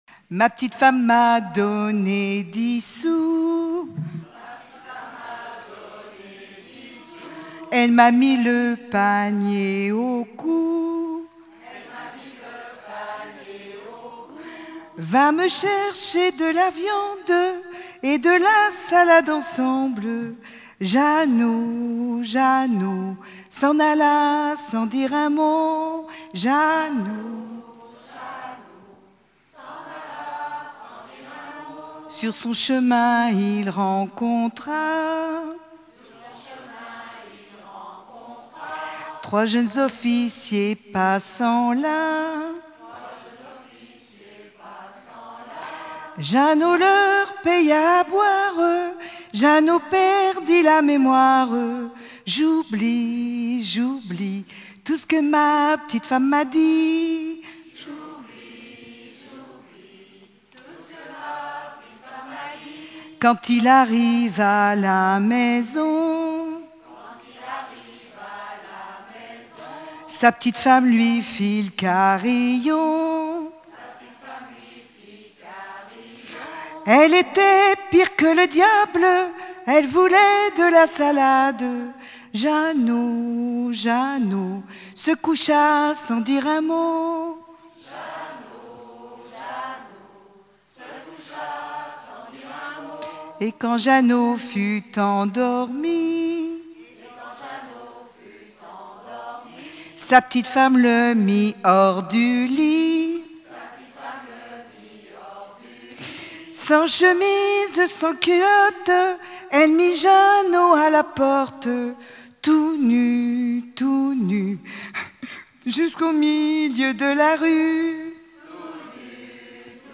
Genre strophique
Festival de la chanson traditionnelle - chanteurs des cantons de Vendée
Pièce musicale inédite